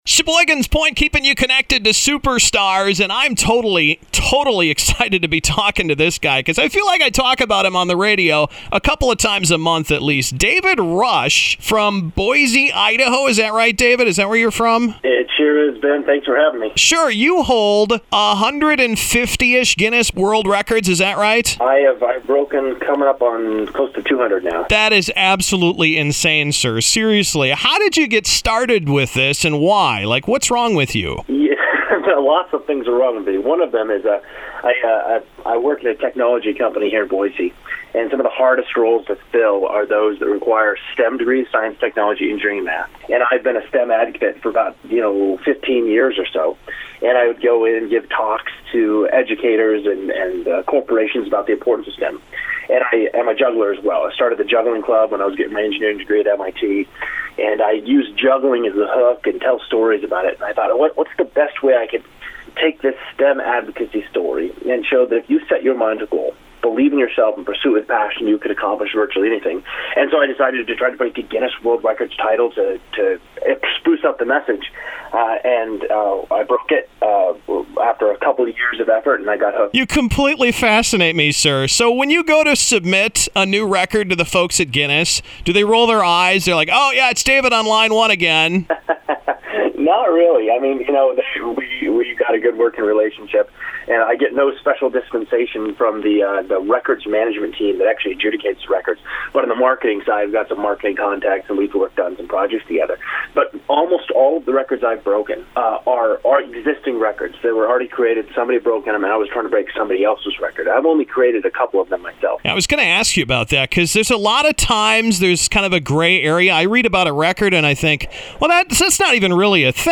I got more curious about this superhero of record breaking and decided to invite him to chat with me on the air…